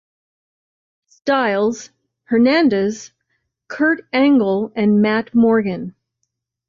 Pronúnciase como (IPA)
/ˈæŋ.ɡəl/